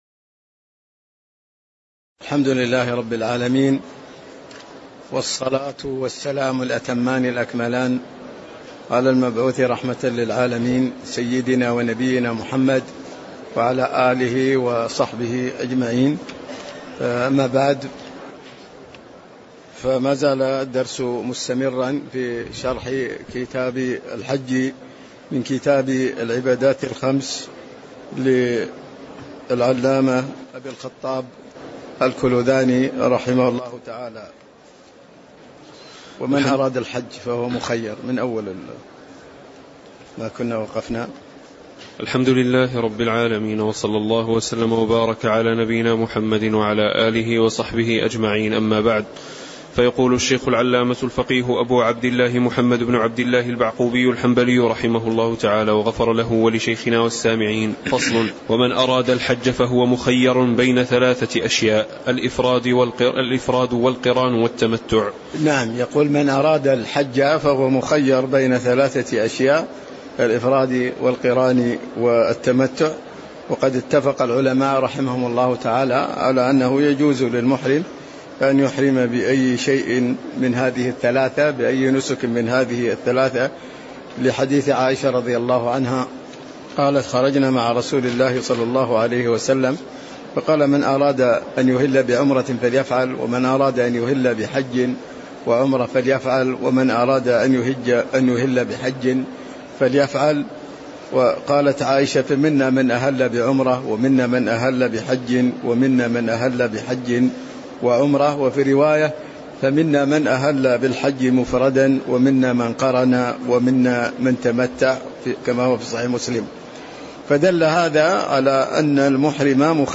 تاريخ النشر ٢ ذو الحجة ١٤٤٤ هـ المكان: المسجد النبوي الشيخ